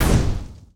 etfx_explosion_fireball2.wav